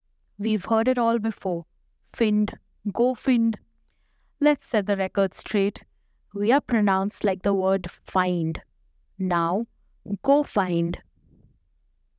GoFynd_Pronunciation.wav